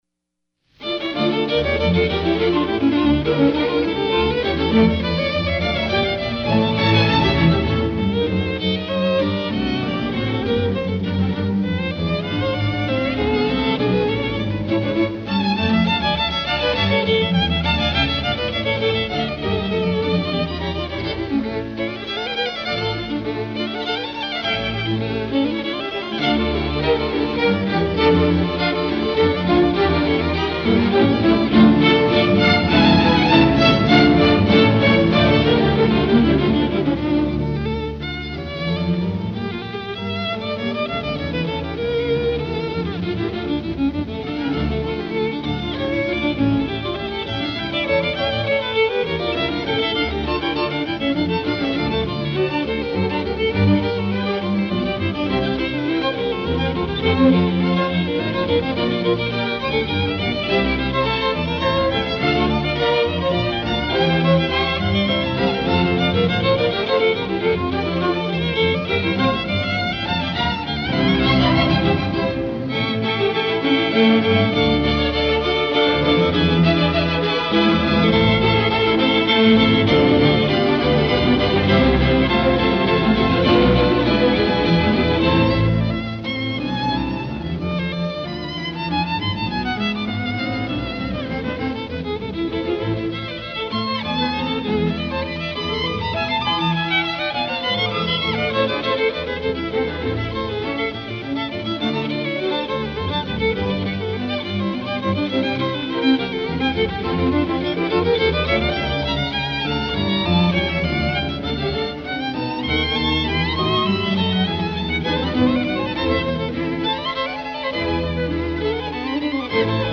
Concerto for 2 violins and orchestra
Concertgebouw Orchestra Amsterdam
Gramophone recording
violin